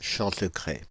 Champsecret (French pronunciation: [ʃɑ̃səkʁɛ]
Fr-Paris--Champsecret.ogg.mp3